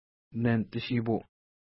Pronunciation: nentə-ʃi:pu:
Pronunciation